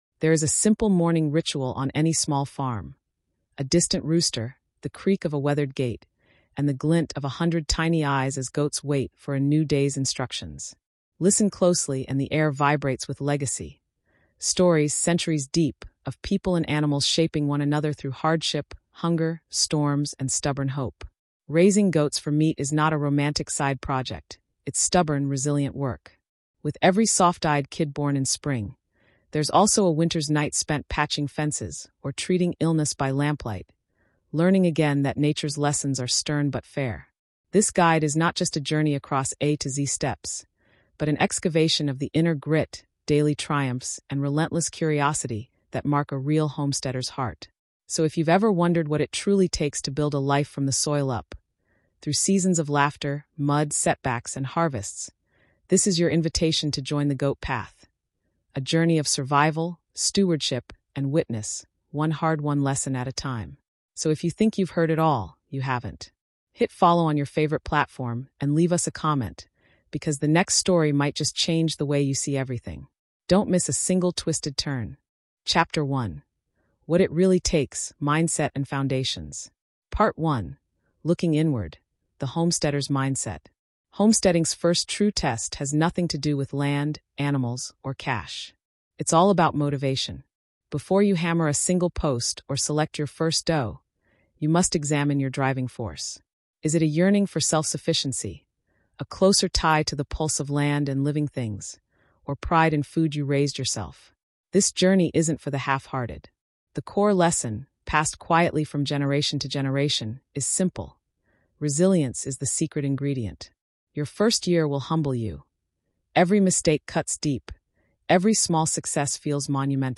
This practical how-to, narrated with warmth and first-hand experience, dives deep into daily routines, hard lessons, and the joy of sustainable self-sufficiency.